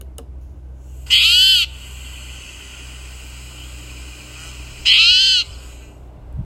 So as you start your hike up the Sandy Trail from the Fay-Luther trailhead, you may hear this call emanating from the bushes…
spotted-towhee-call.m4a